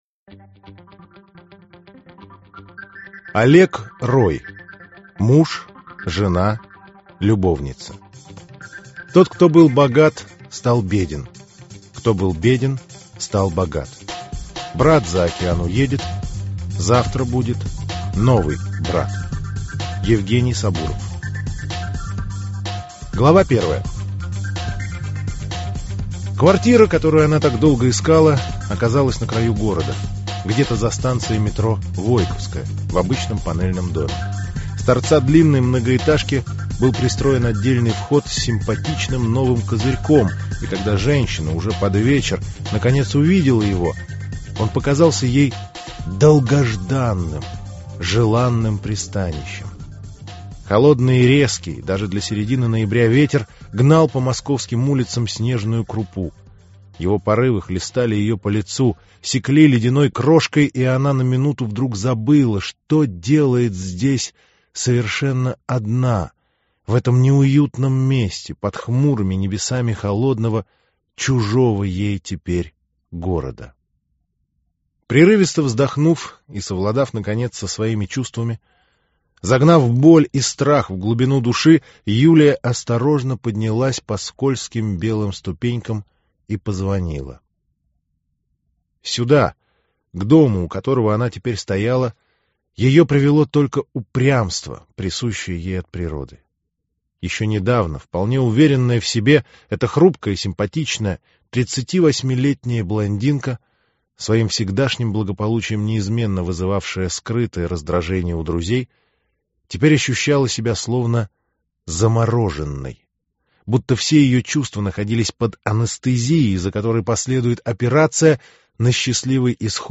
Аудиокнига Муж, жена, любовница | Библиотека аудиокниг